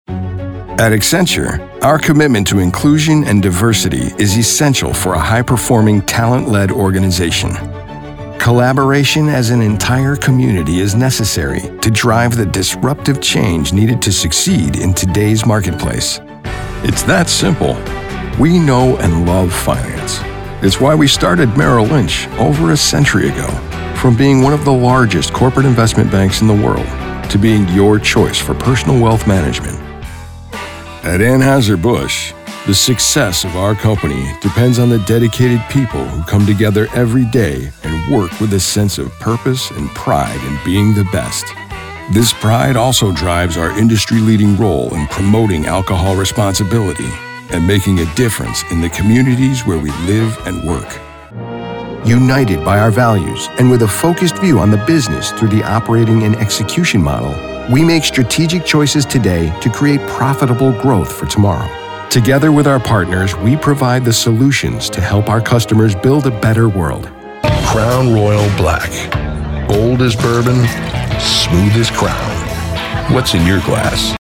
SOURCE-CONNECT Certified US MALE VOICOVER with HOME STUDIO
• BOOTH: Whisper Room, acoustically-treated
gravitas
MIX7-gravitas.mp3